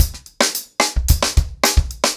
TimeToRun-110BPM.19.wav